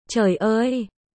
ベトナム語発音
ベトナム語で「驚いた」という表現その①：Trời ơi（チョイオーイ）